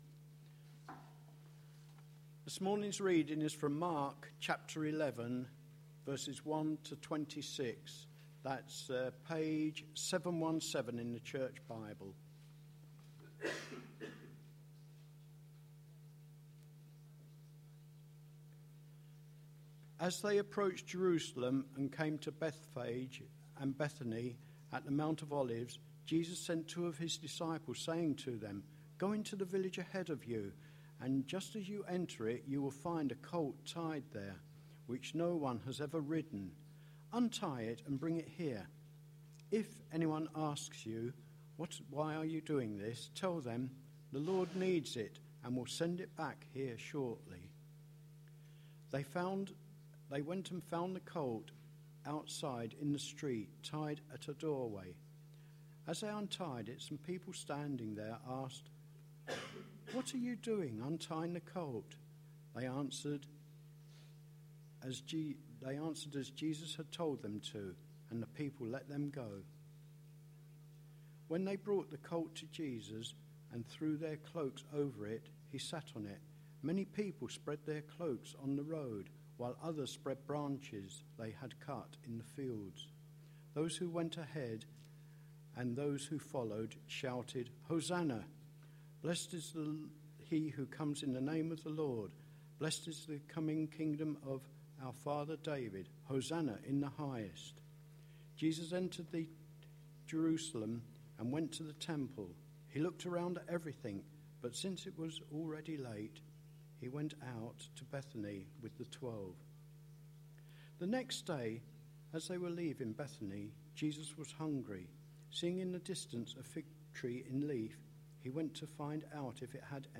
A sermon preached on 26th February, 2012, as part of our Mark series.